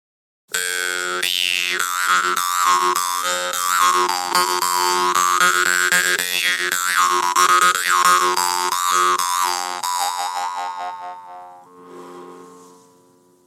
Jew’s Harp, jaw harp, mouth harp
Mungiga eller munharpa, är ett  musikinstrument där tonen frambringas genom att man knäpper på en fjädrande ståltunga som är fastsatt på en ram.
These jaw harp is very dynamic and full of beans. Every source of modulation results in a clear sound.